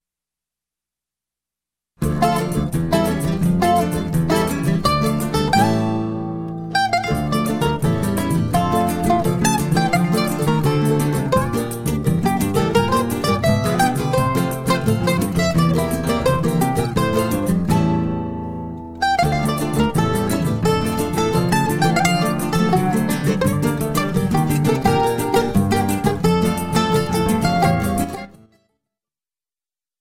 Choro ensemble